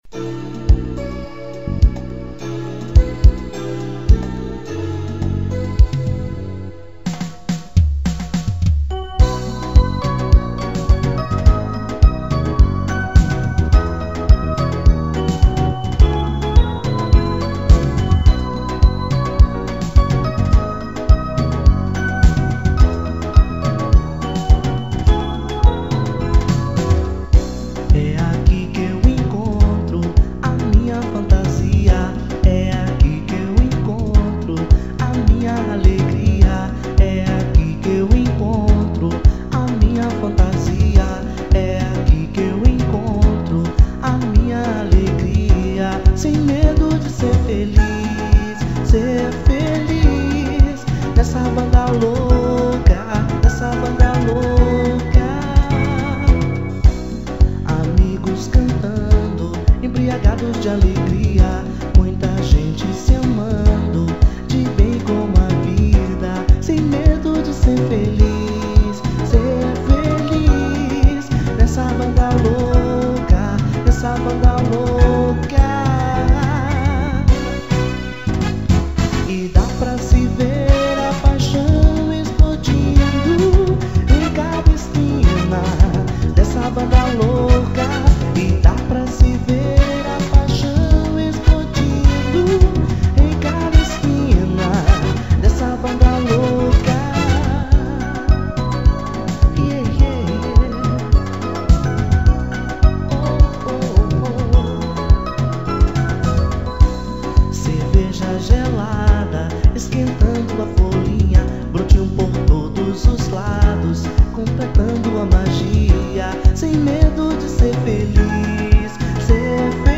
2474   02:59:00   Faixa: 9    Machinha